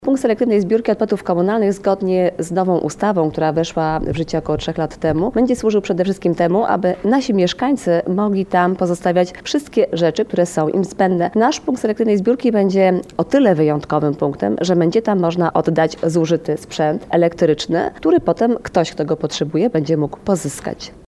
– Dzięki dodatkowym środkom w połowie przyszłego roku na terenie gminy Kampinos powstanie Punkt Selektywnego Zbierania Odpadów Komunalnych – podkreśla wójt gminy Monika Ciurzyńska.